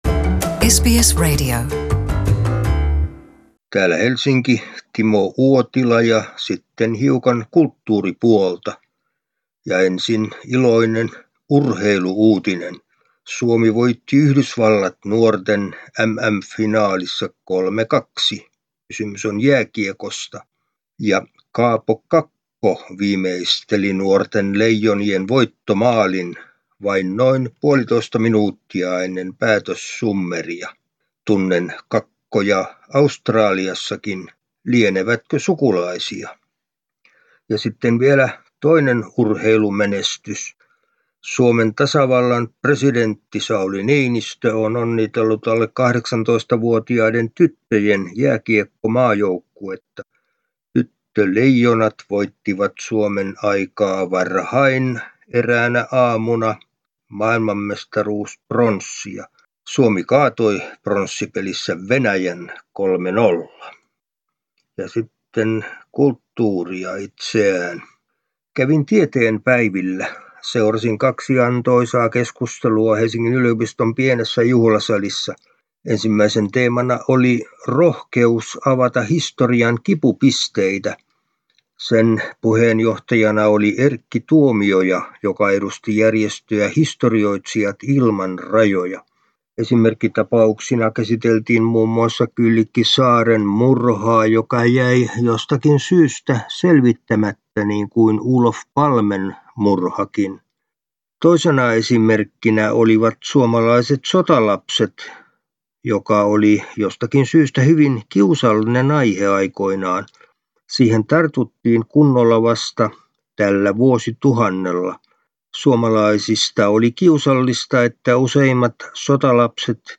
urheilu- ja kulttuuriraportti